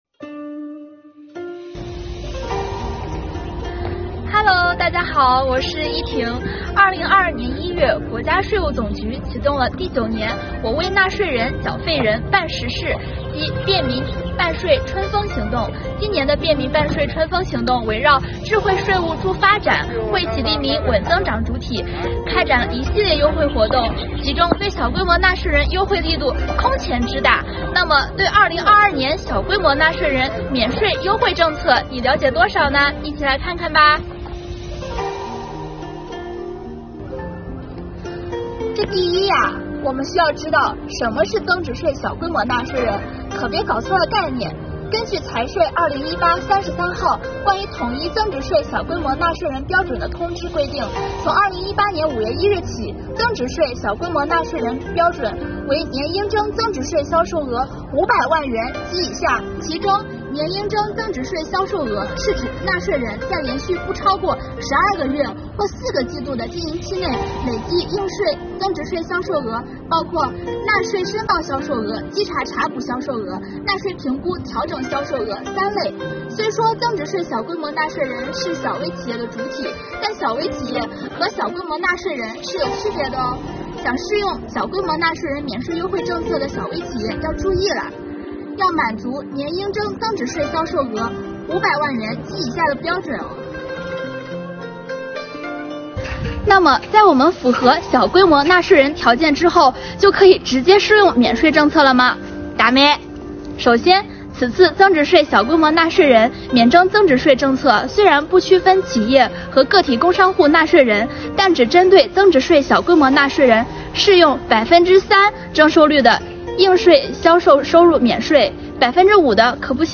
我们以小课堂的形式，对2022年小规模纳税人免税优惠政策进行详细解读